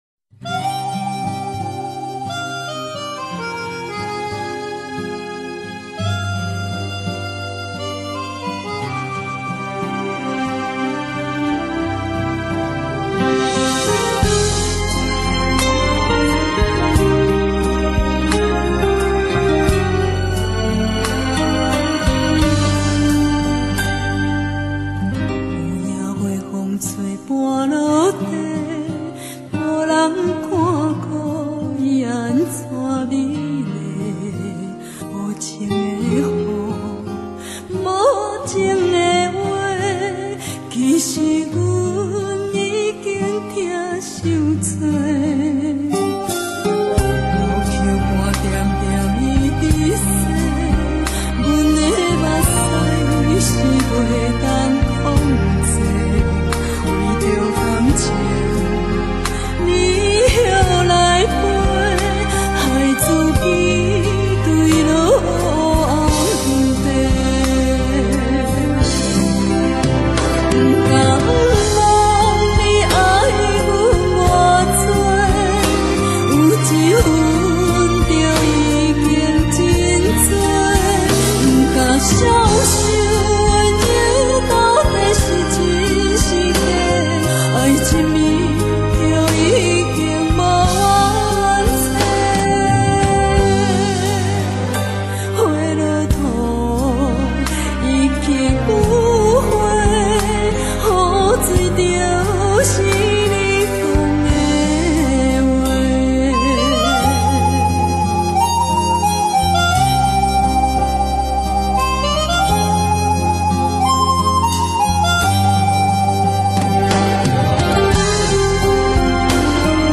特色鼻腔共鸣音